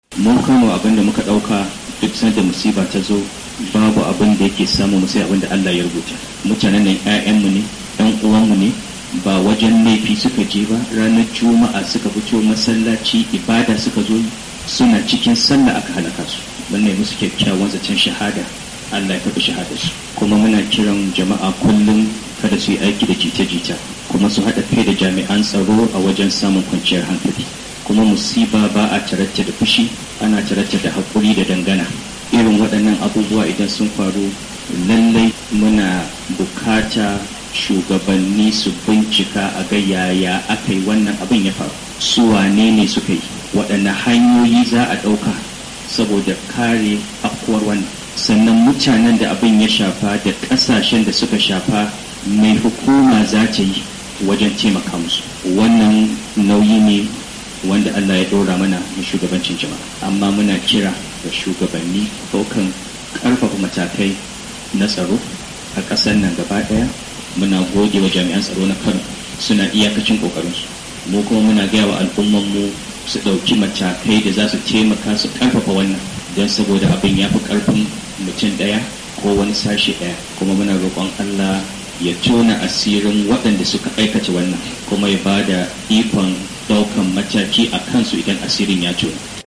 A jawabin da yayi lokacin da yake tarbar mataimakin shugaban Najeriya, Namadi Sambo, wanda ya kai ziyarar jaje a fadarsa, Sarki Sanusi ya ce su a Kano, sun yarda cewa babu wata masifar da zata fada musu sai abinda Allah Ya rubuta musu.